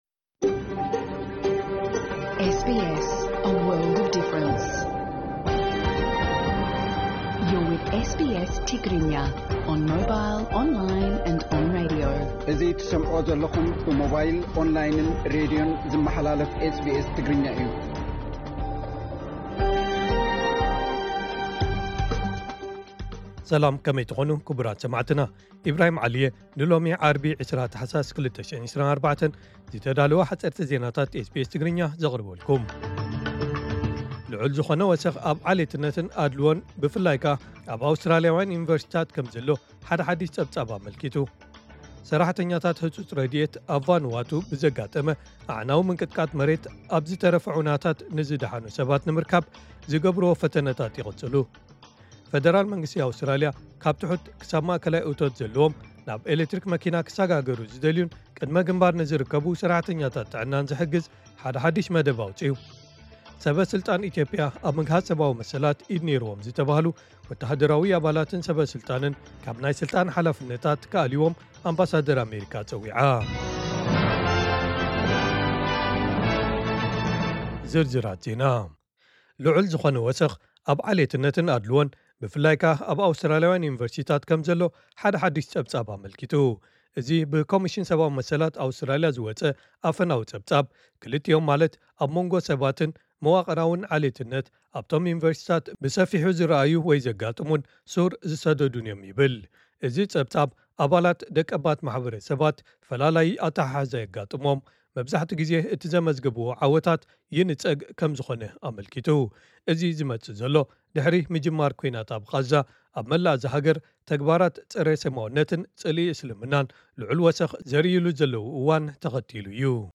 ዕለታዊ ዜና ኤስቢኤስ ትግርኛ (20 ታሕሳስ 2024)